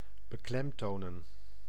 Ääntäminen
Netherlands: IPA: [bəˈklɛmtonə]